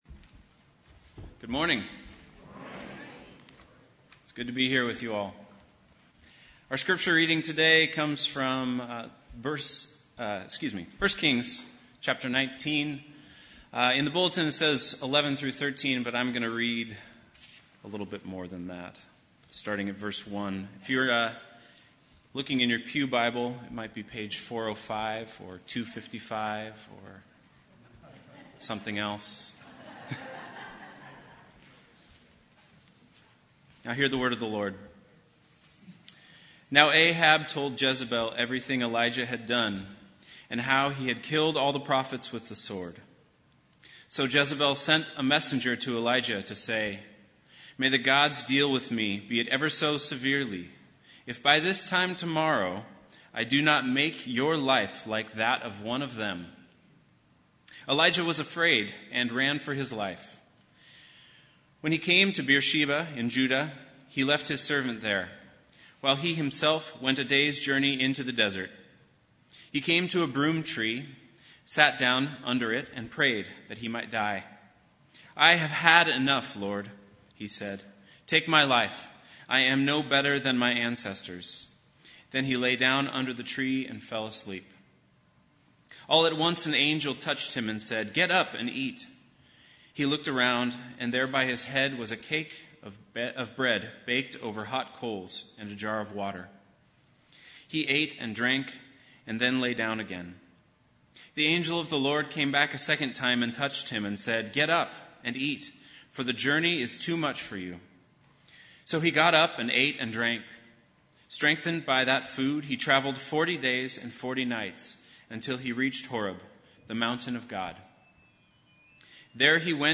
Bible Text: 1 Kings 19:1-13 | Preacher